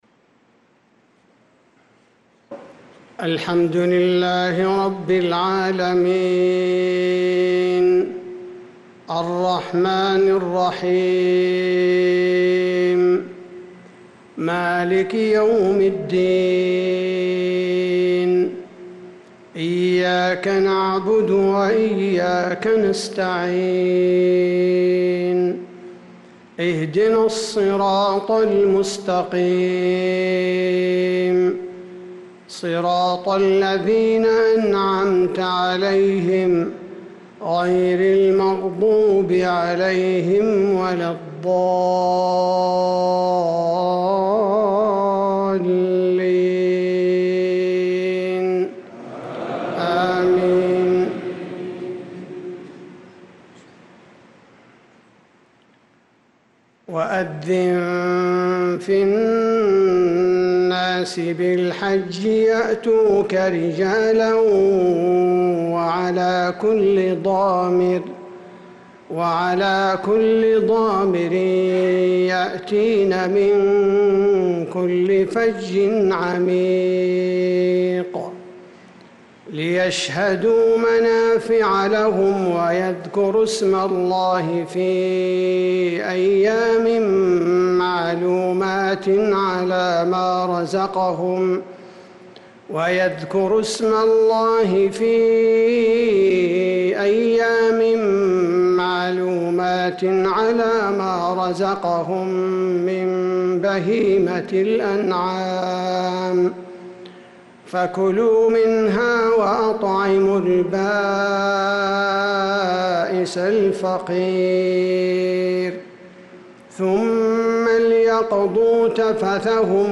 صلاة العشاء للقارئ عبدالباري الثبيتي 4 ذو الحجة 1445 هـ
تِلَاوَات الْحَرَمَيْن .